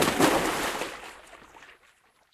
water_splash02.wav